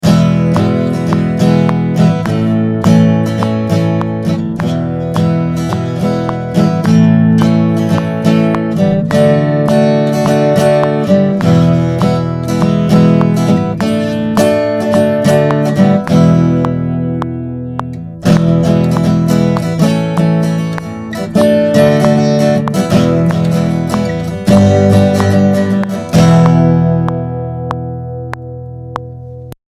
Für den Test habe ich eine Akkordfolge mit der Akustikgitarre ohne Metronom-Klick eingespielt. Um die Tempoerkennung auf die Probe zu stellen, gibt es am Schluss plötzlich einen schnelleren, weniger gleichmäßig gespielten Teil.
Der nachträglich eingeschaltete Metronom-Klick richtet sich im ersten Teil sehr gut nach dem freien Tempo, im zweiten Teil hat es nicht funktioniert.
01_Gtr_mit_Klick.mp3